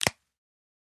身近な生活音系の効果音がダウンロードできます。
携帯ガラケーを閉じる音
keitai1_close.mp3